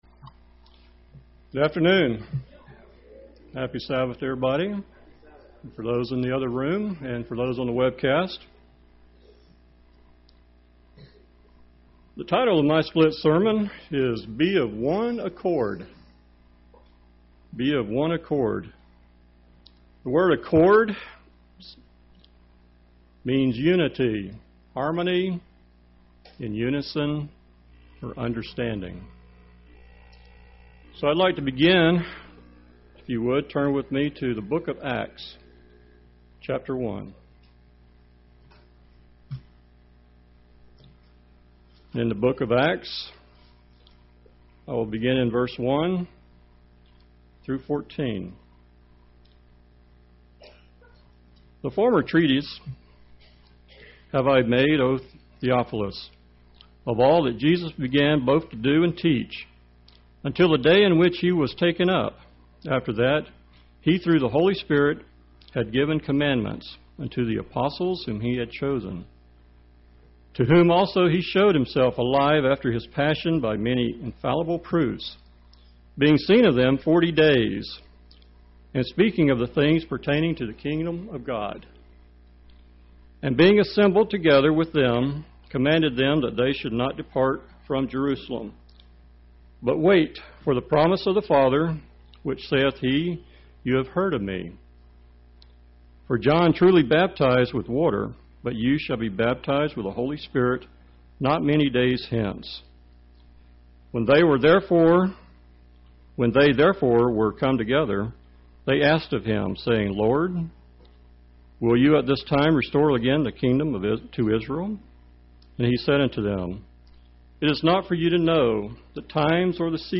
UCG Sermon Studying the bible?
Given in Tampa, FL